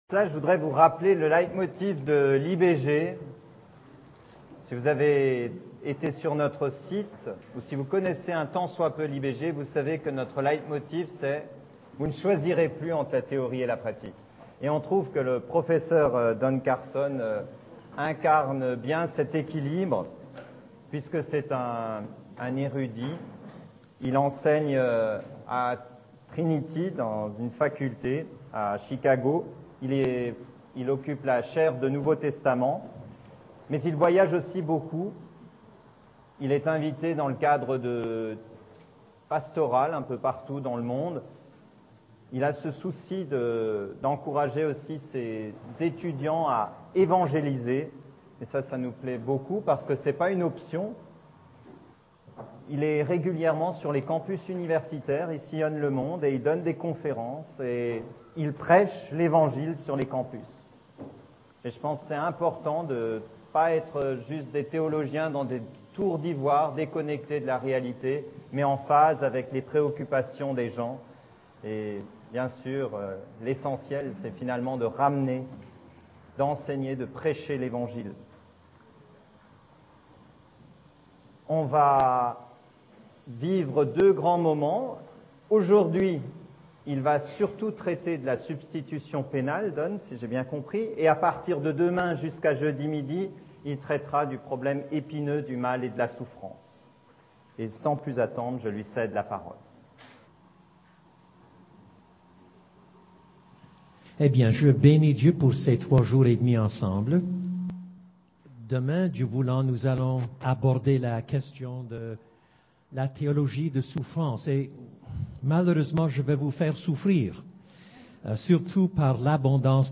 Conférence 4
Prédicateur : Don CARSON